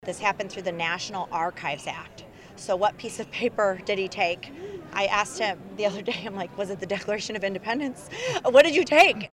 Statewide Iowa — The Iowa Republican Party’s fundraiser on August 10th showcased the GOP’s top of the ticket candidates this November and focused on the presidential campaign that will soon follow.